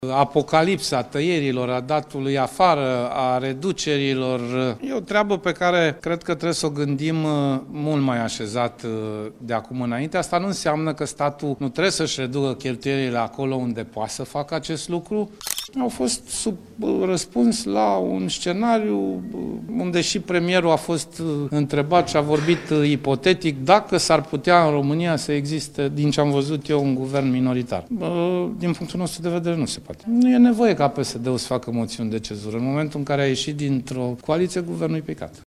În cadrul aceleiași conferințe de presă susținute la Parlament, liderul PSD a precizat că analiza internă a partidului privind continuarea sau retragerea de la guvernare va fi încheiată odată cu adoptarea bugetului.